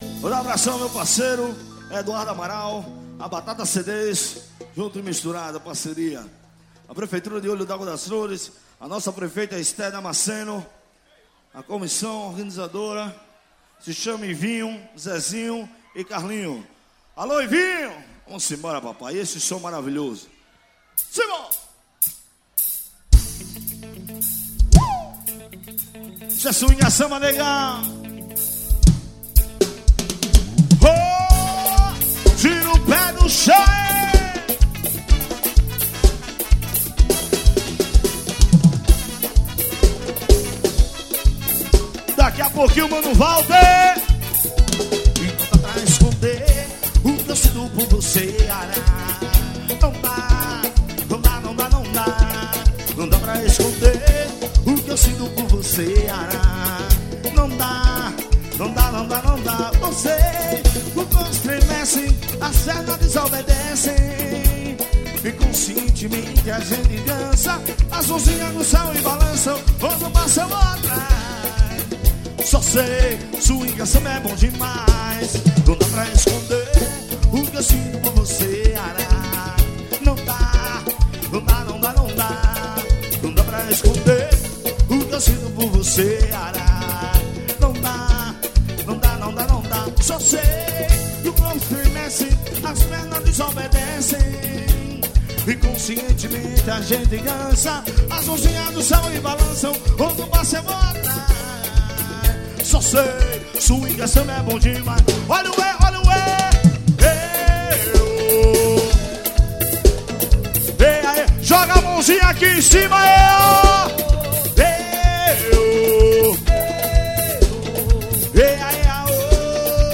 AXÉ.